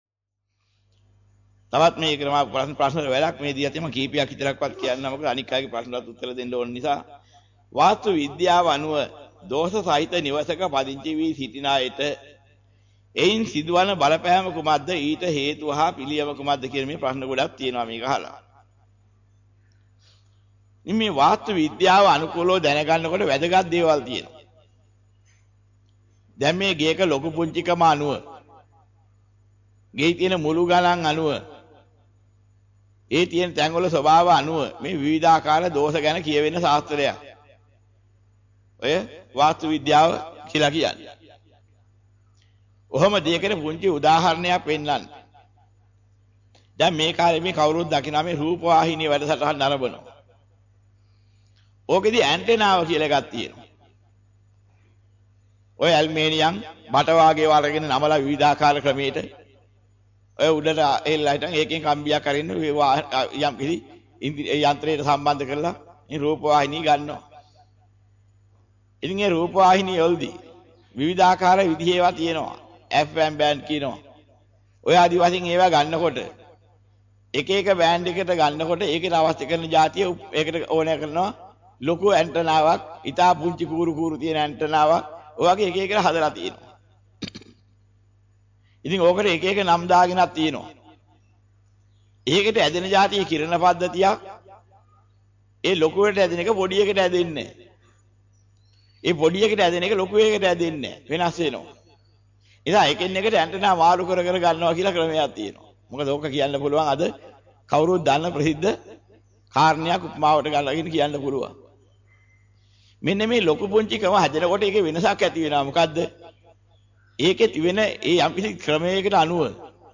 වෙනත් බ්‍රව්සරයක් භාවිතා කරන්නැයි යෝජනා කර සිටිමු 09:12 10 fast_rewind 10 fast_forward share බෙදාගන්න මෙම දේශනය පසුව සවන් දීමට අවැසි නම් මෙතැනින් බාගත කරන්න  (4 MB)